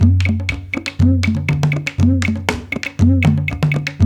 120 -CONG01R.wav